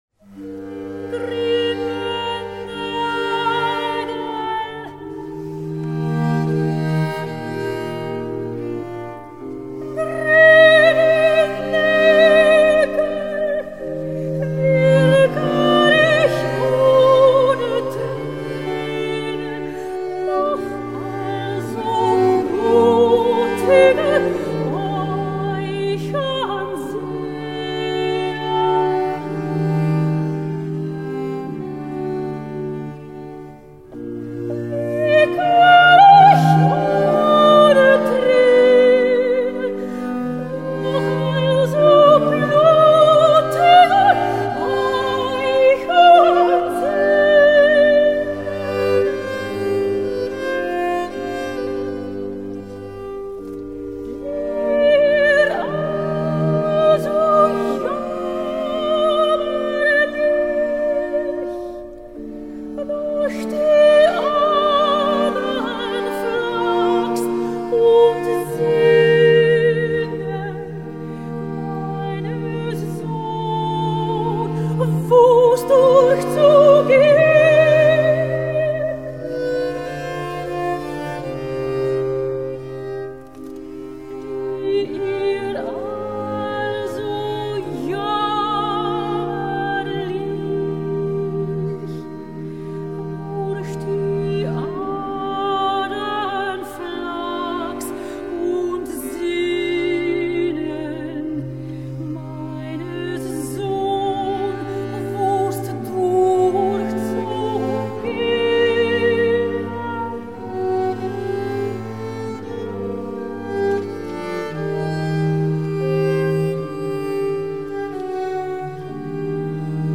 Il Lutto dell'Universo (the mourning of the Universe), a true church opera requiring a large Baroque orchestra; or Sig des Leydens Christi über die Sinnligkeit (The victory of our Lord's suffering over sensuousness) for a smaller ensemble, equally poignant.